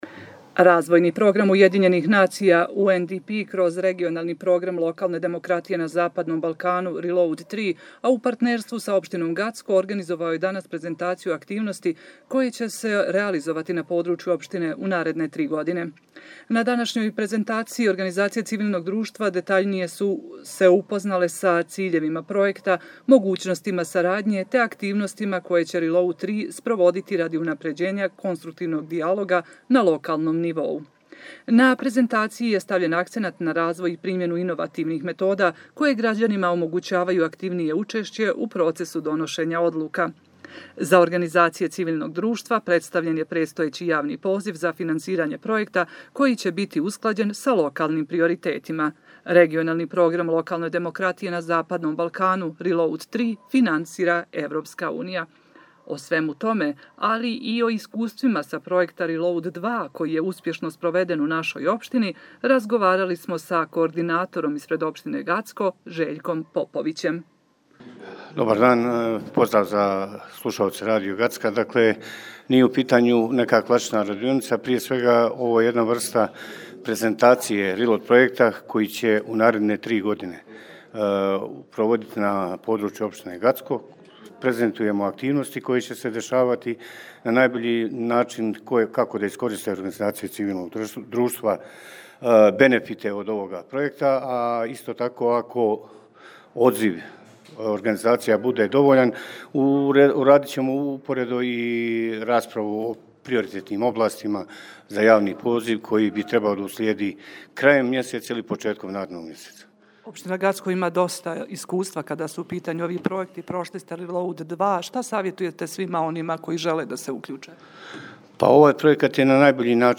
разговарали смо